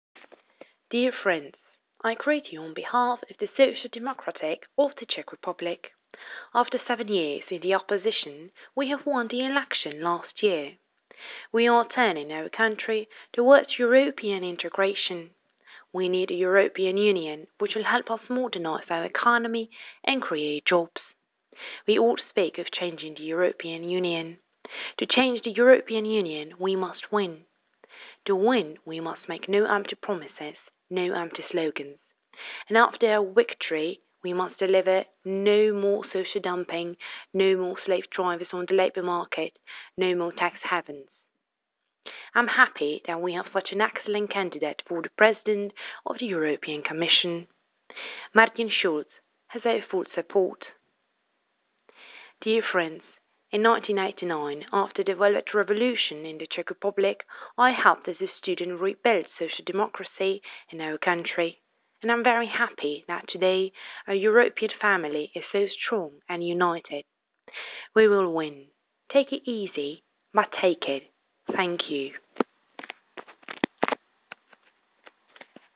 srovnání přednesení stejného příspěvku s různou výslovností:-)
Sobotkův projev_přemluven
Sobotkův-projev_přemluven.wav